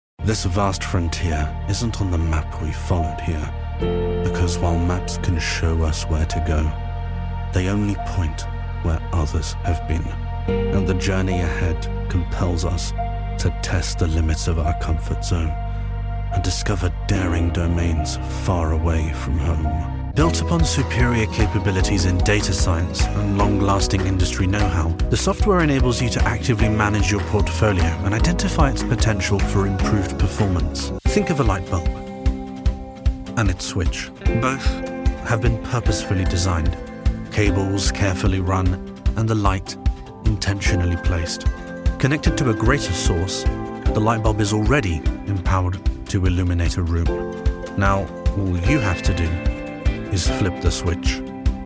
Versatile English voice over, ranging from warm and engaging to theatrical, funny or conversational.
Sprechprobe: Industrie (Muttersprache):